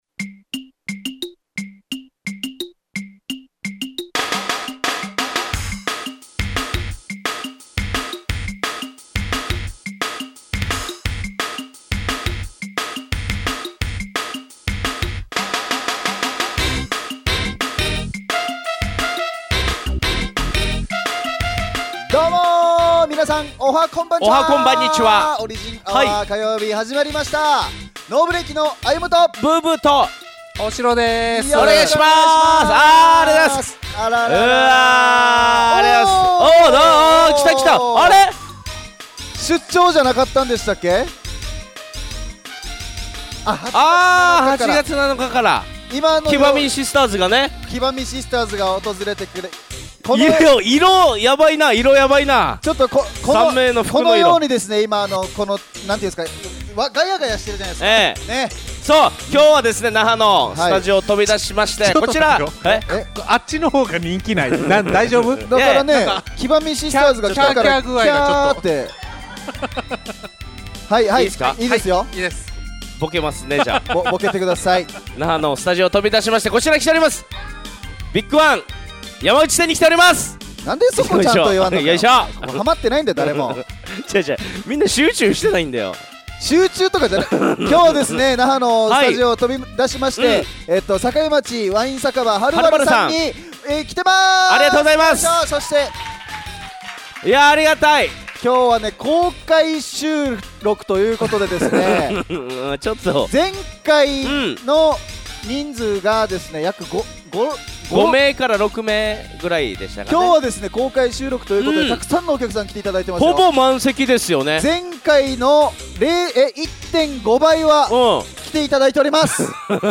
160712ノーブレーキのオリジンアワー公開収録 in ハル晴ル